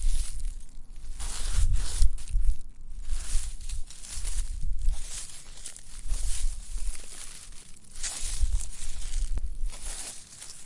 微风树
描述：风树叶子花园农村
Tag: 花园 树叶 contryside